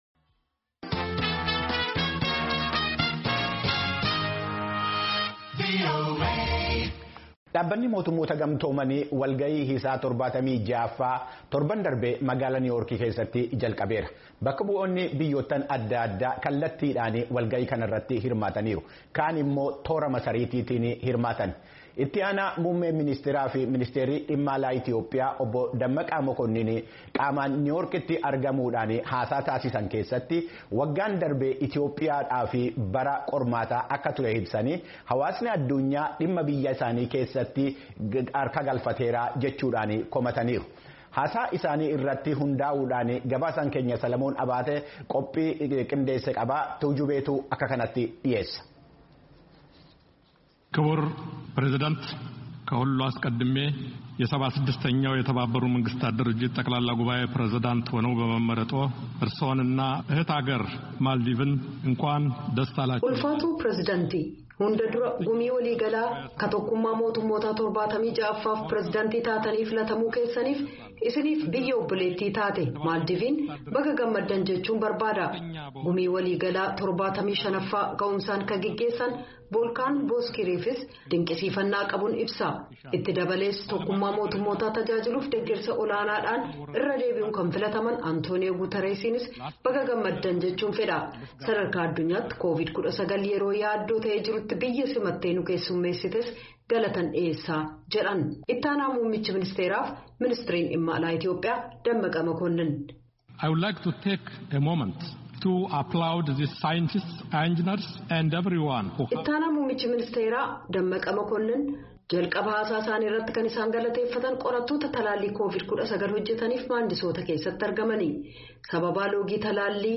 Ministriin Dhimma Alaa Itiyoophiyaa obbo Demmeqee Mekonnin Gumii Walii Galaa Kan Tokkummaa Mootummootaaf Ibsa Kennan